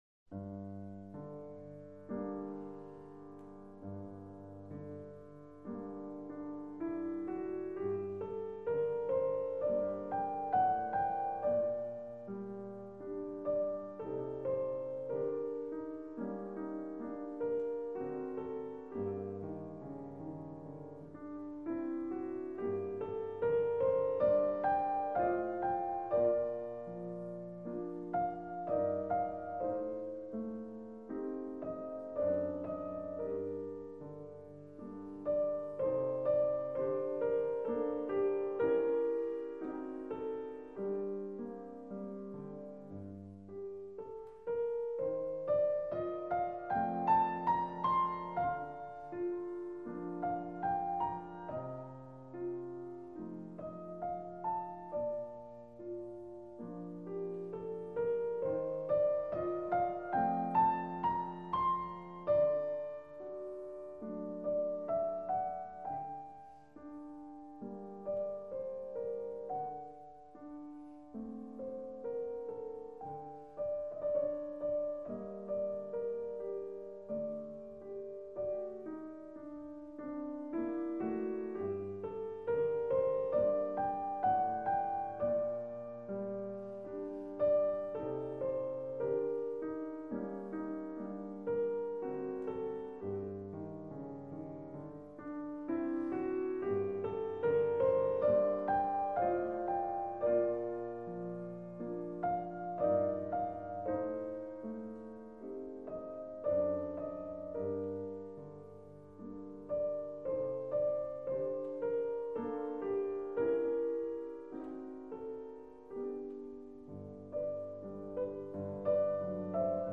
钢琴独奏